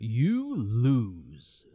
Voices / Male / You Lose.wav
You Lose.wav